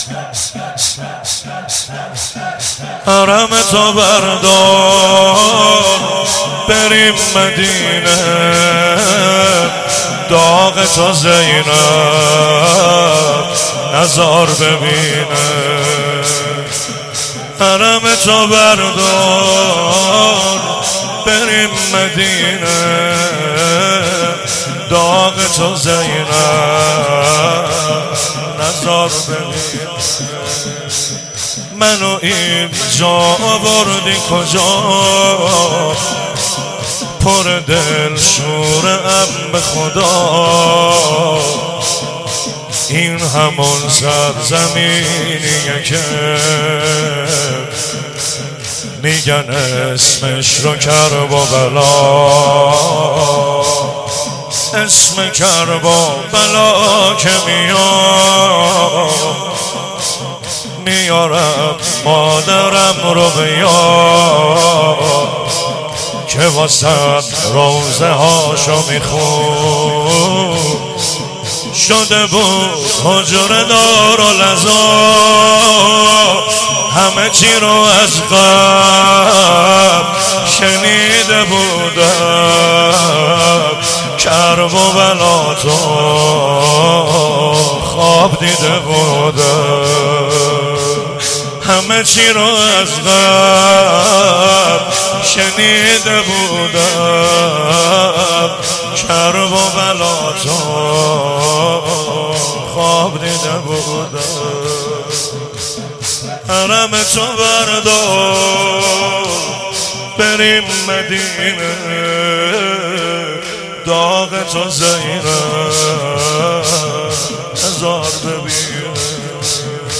شب دوم محرم 92, هیئت علمدار